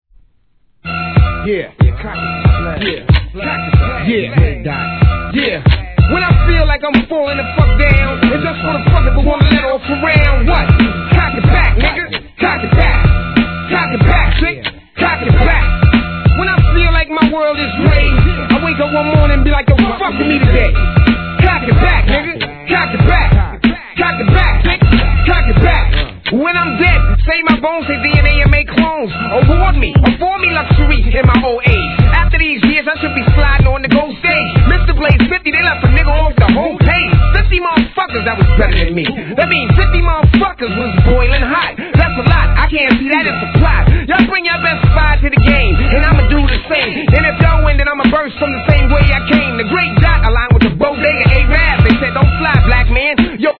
1. HIP HOP/R&B
拳銃の引き金を引く音を効果的にサンプリングしたハーコーSHIT!!